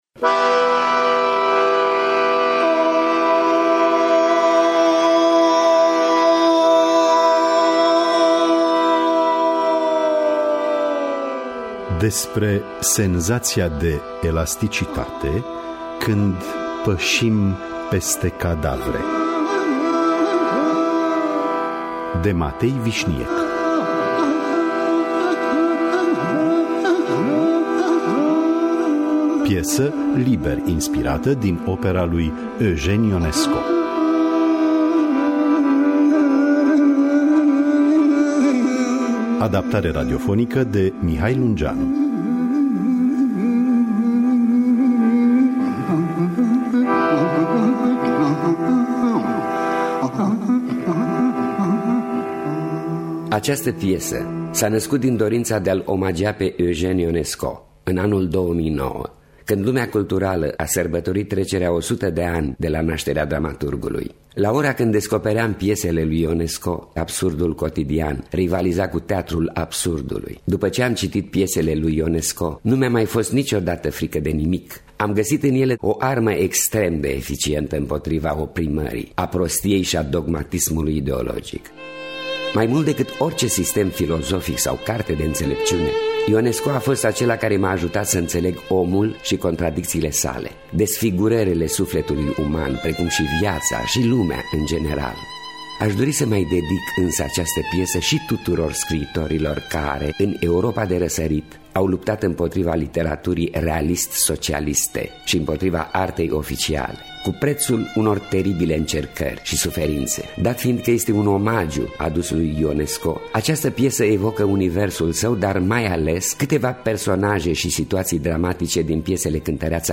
piesă liber inspirată din opera lui Eugène Ionesco.